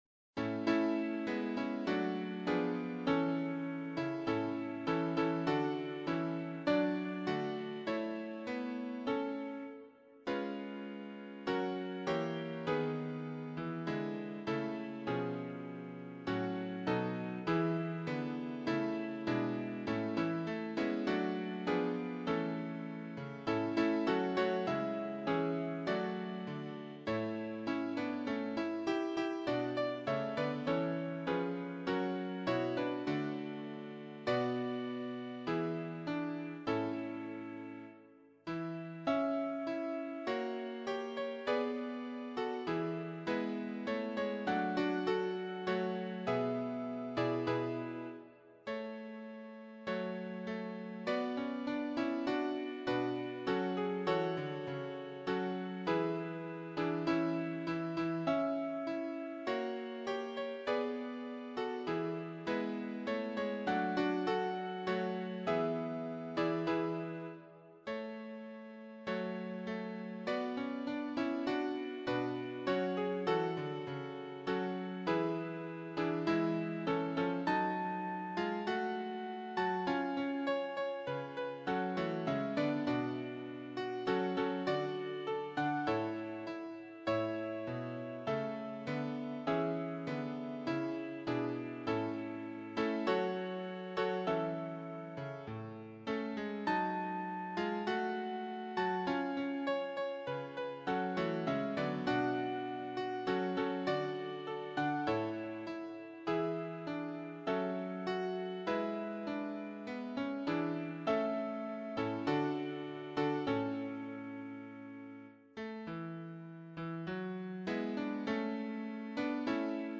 choir SATB
Anthem